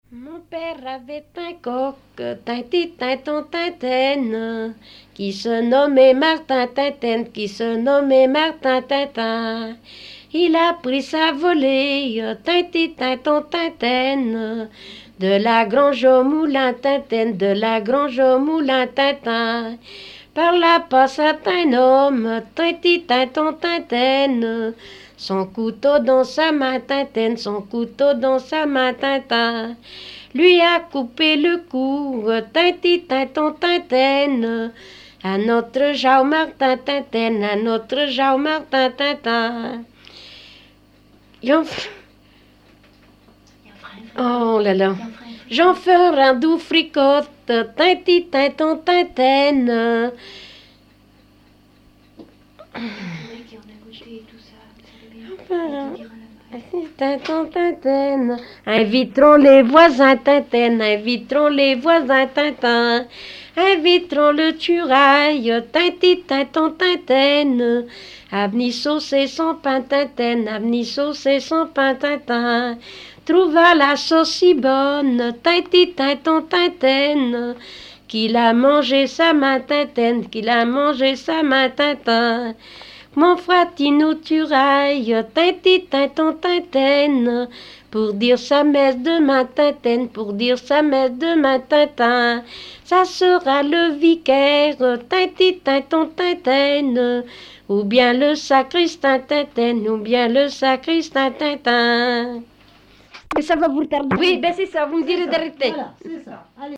Barbâtre ( Plus d'informations sur Wikipedia ) Vendée
danse : ronde
Pièce musicale inédite